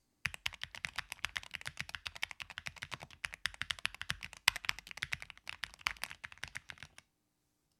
Sound Test
The Wisteria Tactile Switches emit a beautiful creamy click that I find incredibly satisfying.
It is a little loud, and the sound might annoy the people around you.
epomakersoundcheck.mp3